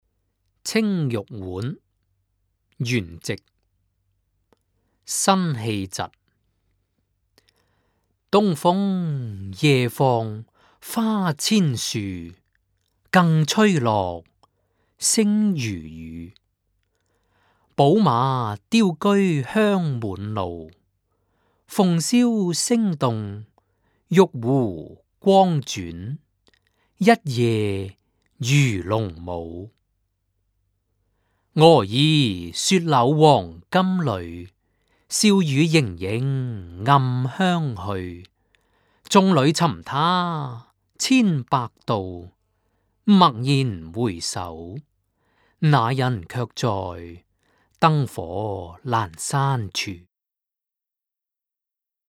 原文誦讀錄音
(粵語台詞誦)